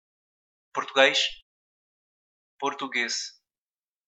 Pronunciation[puɾtu'geʃ] (European), [poɾtu'gejs] (Brazilian)